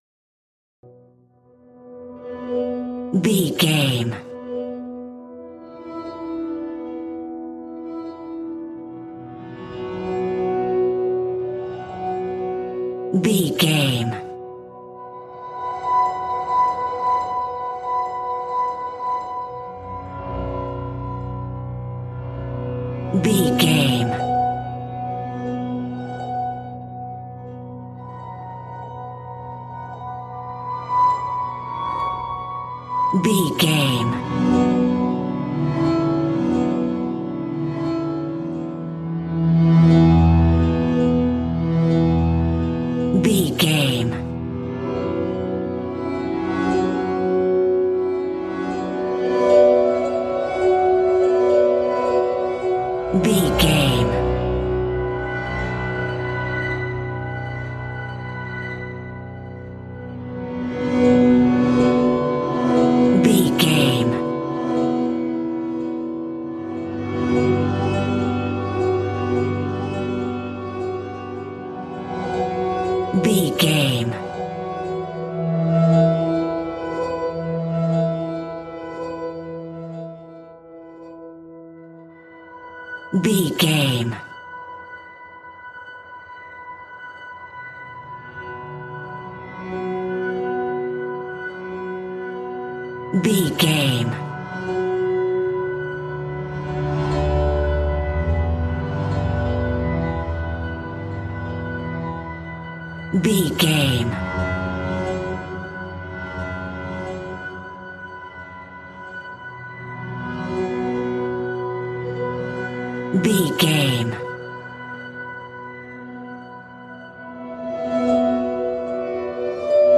Scary Reverse Piano Sounds.
Aeolian/Minor
ominous
eerie
horror